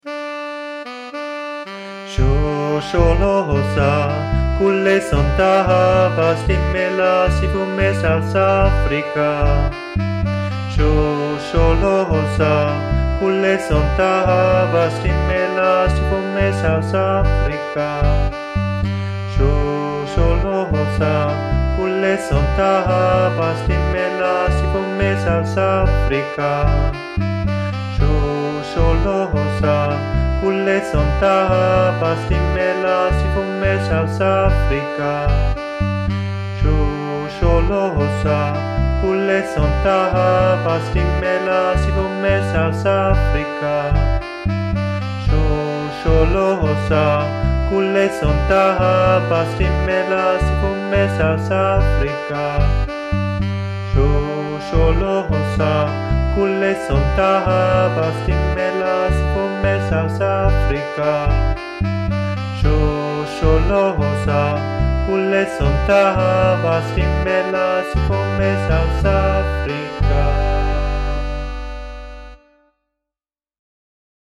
Übungsdatei 2. Stimme
3_shosholoza_alt.mp3